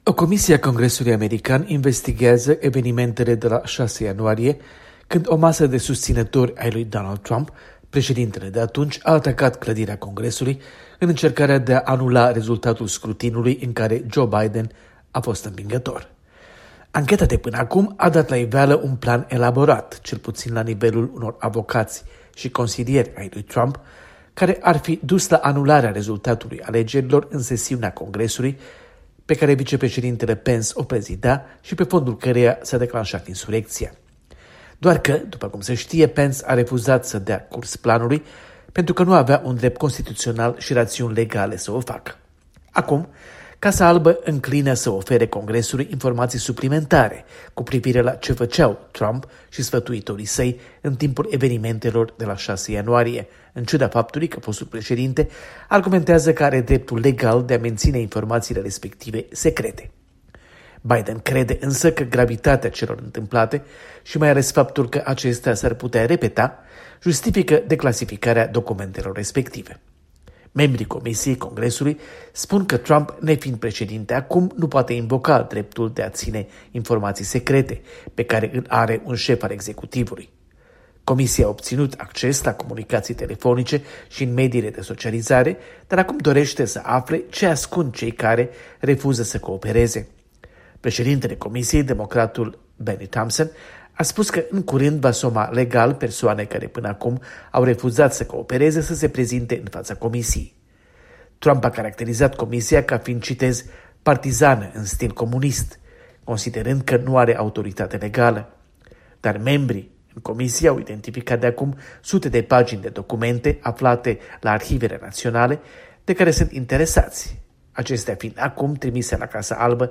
Corespondența de la Washington